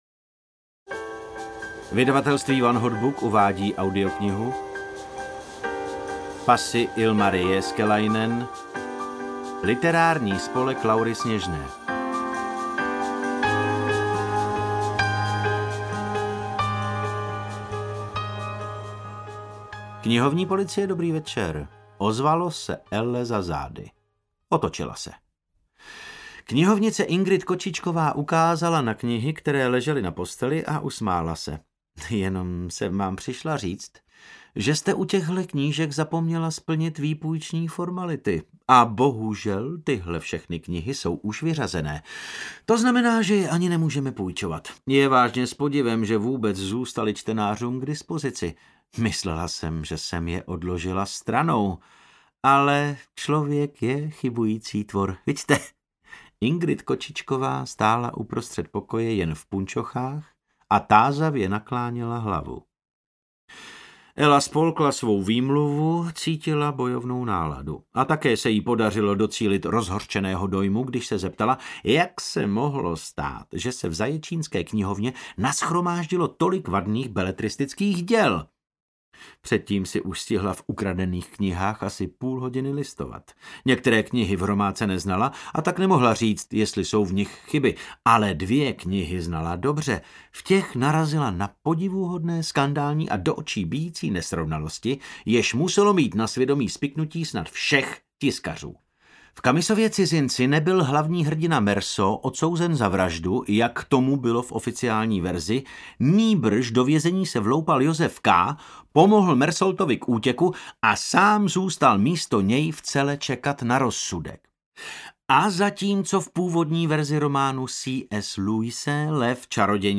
Literární spolek Laury Sněžné audiokniha
Ukázka z knihy
• InterpretDavid Novotný